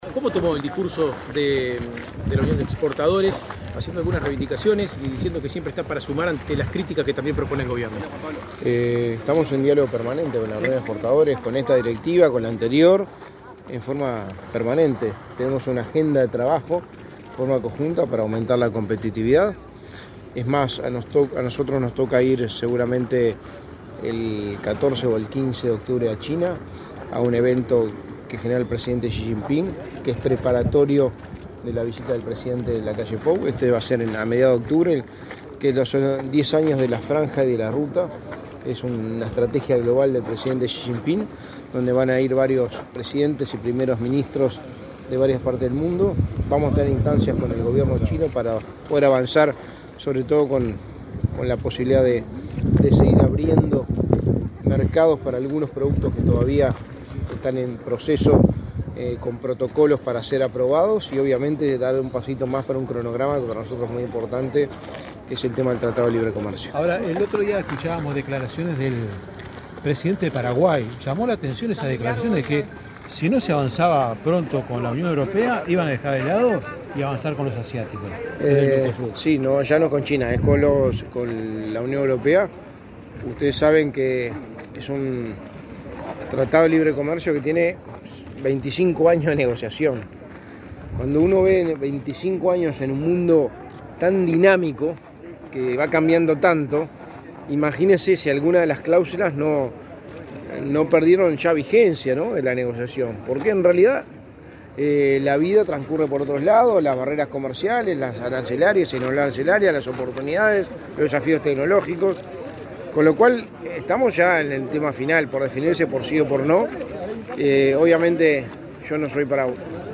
Declaraciones del secretario de Presidencia, Álvaro Delgado | Presidencia Uruguay
El secretario de la Presidencia, Álvaro Delgado, dialogó con la prensa, luego de participar, este jueves 28 en Montevideo, en la celebración por el